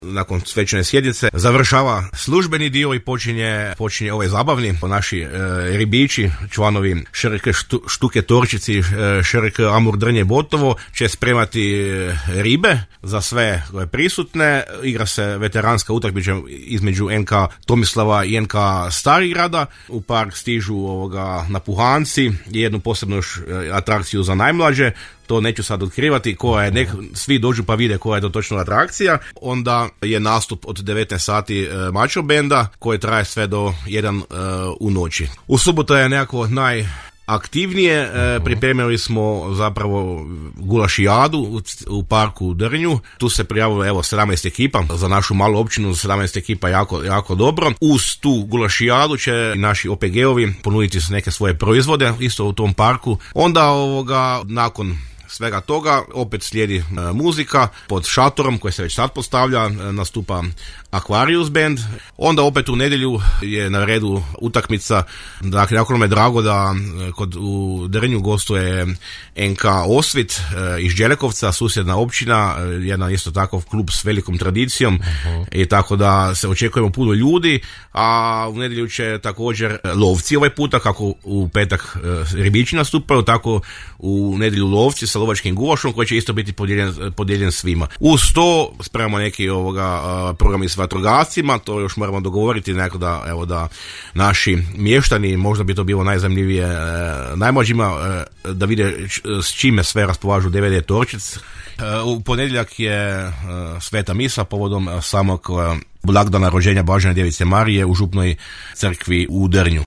Centralni dio proslave će biti u petak postavljanjem spomen ploče na spomenik u Drnju, uz kratko predavanje u organizaciji Družbe “Braća Hrvatskoga Zmaja”, a potom i svečanom sjednicom Općinskog vijeća, što je u emisiji Susjedne općine u programu Podravskog radija najavio načelnik Općine Drnje Matija Dolenec;